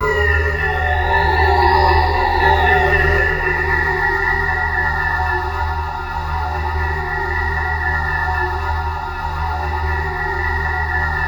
TENSION03.-L.wav